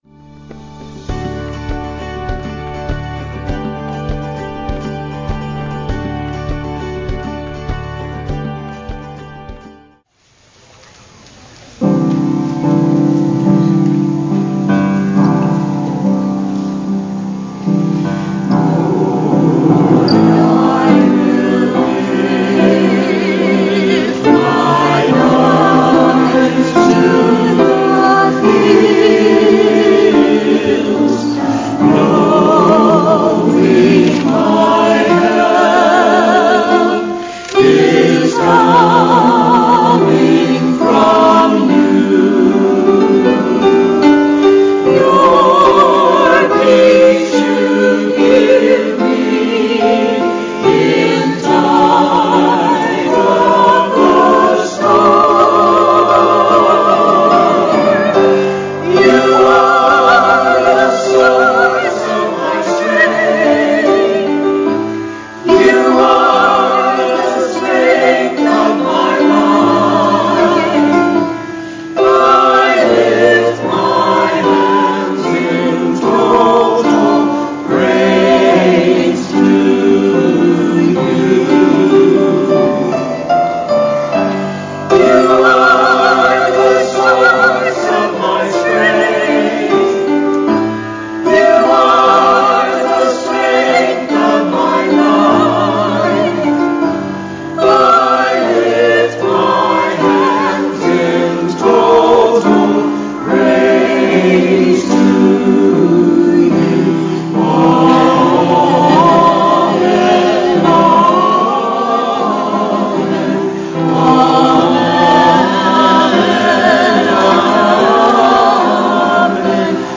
“God’s Blessings” Worship Service – October 20th, 2019 Length 36:32